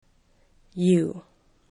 you    ju